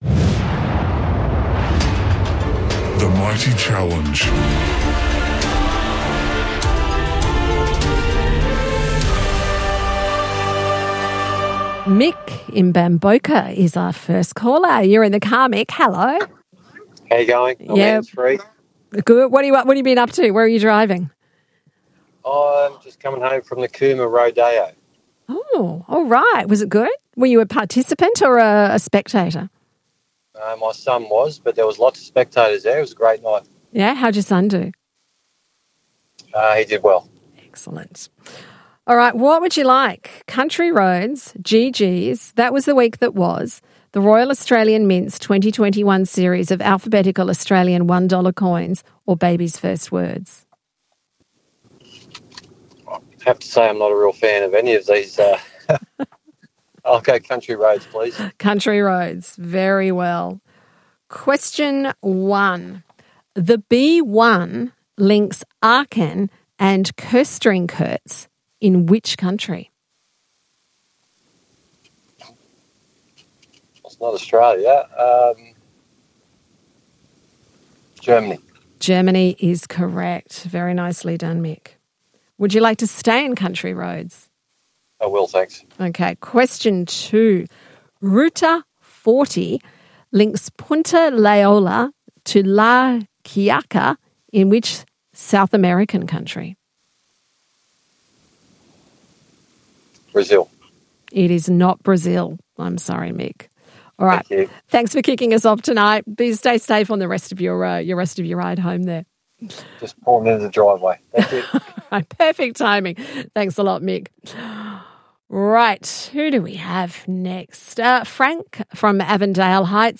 Are you into your trivia? Calling all connoisseurs of cryptic to the only quiz played live, all around Australia.